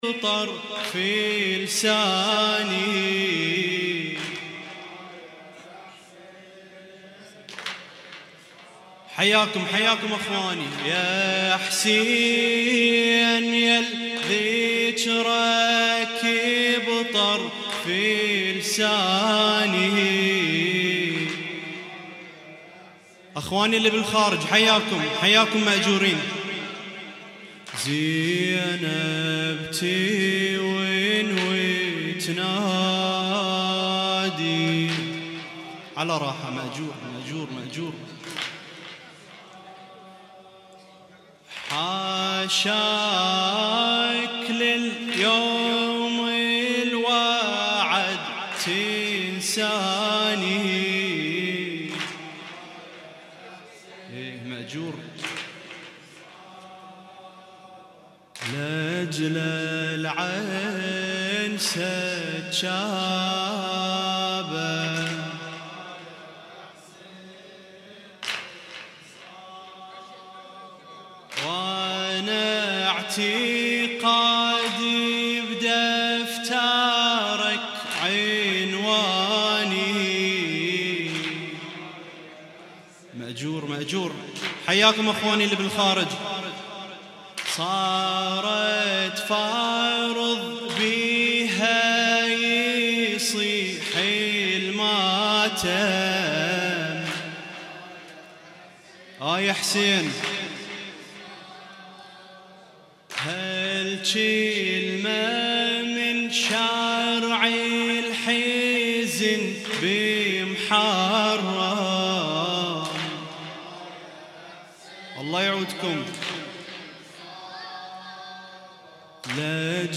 تغطية صوتية: ليلة ثاني محرم 1438هـ في المأتم